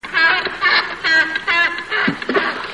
Kormoran czubaty - Phalacrocorax aristotelis
głosy